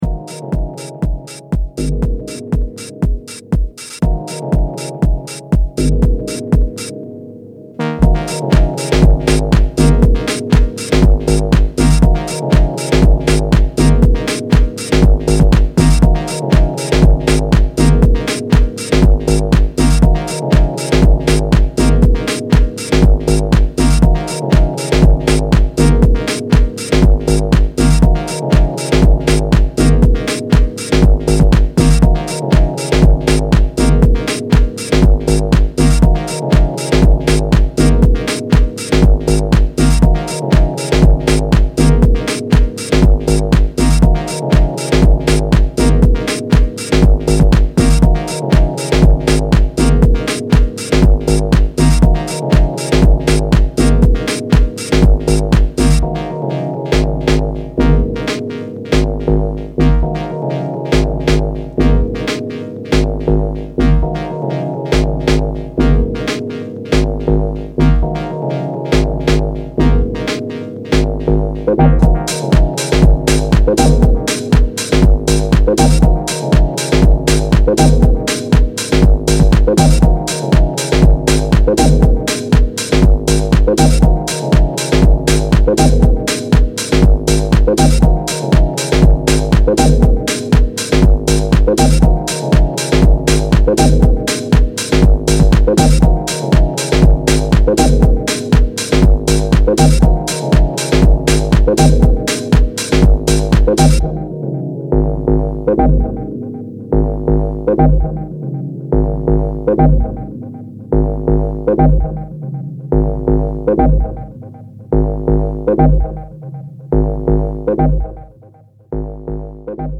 sample heavy and hands in the air deep house